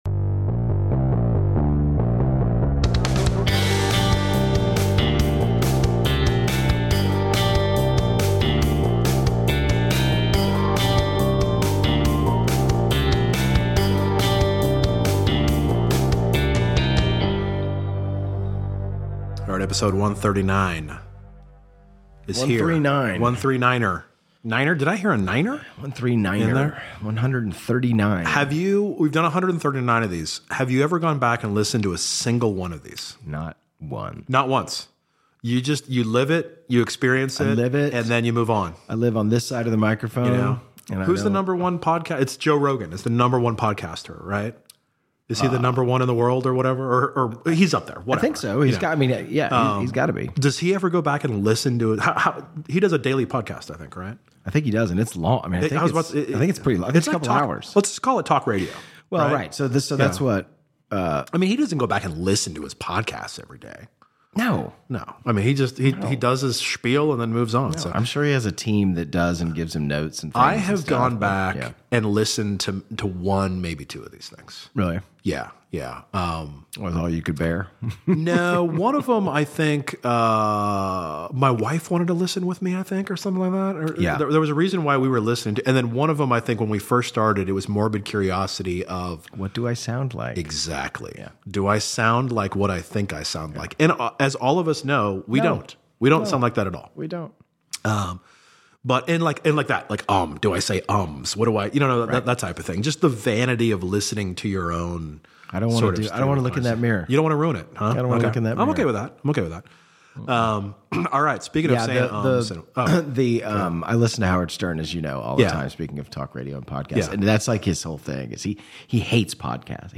Tune in for an engaging discussion filled with humor, insights, and a touch of tradition.